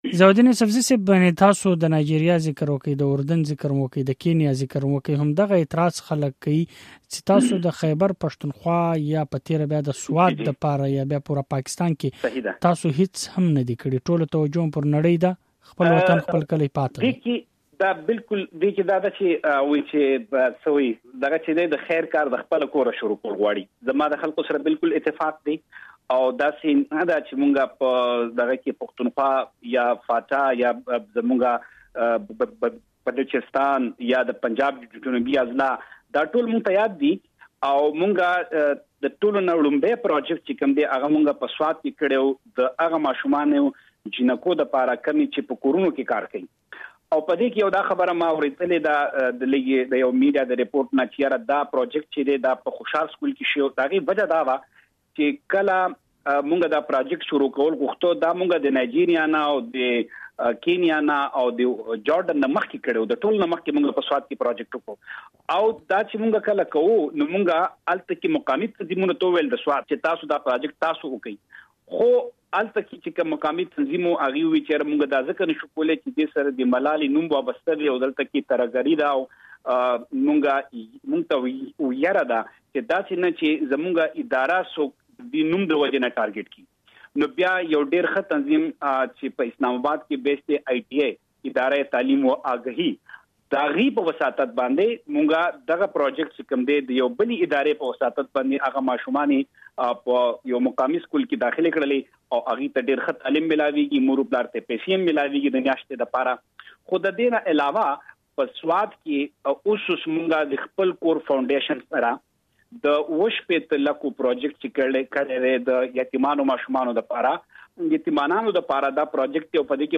پر دې هر څه مو د ملالې یوسفزۍ له پلار او د ملاله فنډ له مشر ضیا الدین یوسفزي سره مفصله مرکه کړې ده چې تاسې یې د غږ (ږغ) په ځای کې اورېدای شئ.